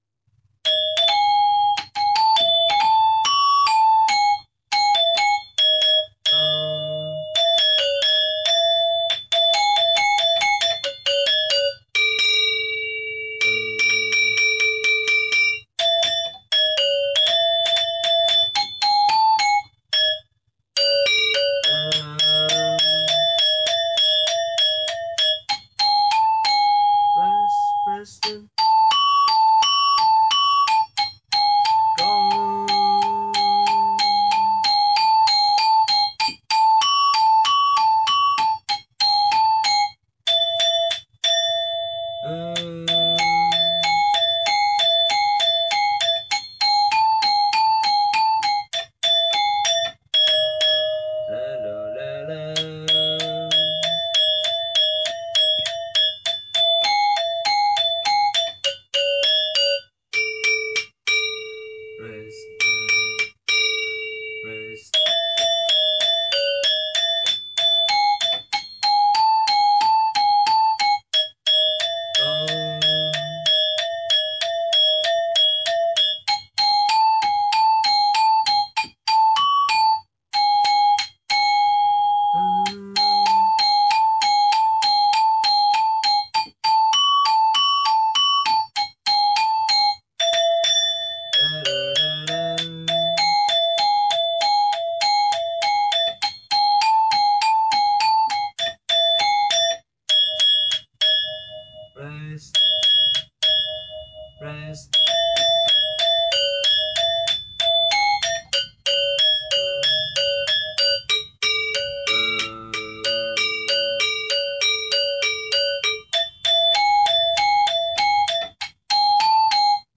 I play Balinese gamelan music in Los Angeles as a member of Sanggar Tujunga.
Rehearsal Recordings